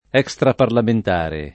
vai all'elenco alfabetico delle voci ingrandisci il carattere 100% rimpicciolisci il carattere stampa invia tramite posta elettronica codividi su Facebook extraparlamentare [ H k S traparlament # re ] o estraparlamentare agg. e s. m. e f.